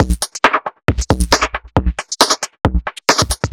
Index of /musicradar/uk-garage-samples/136bpm Lines n Loops/Beats
GA_BeatFilterB136-03.wav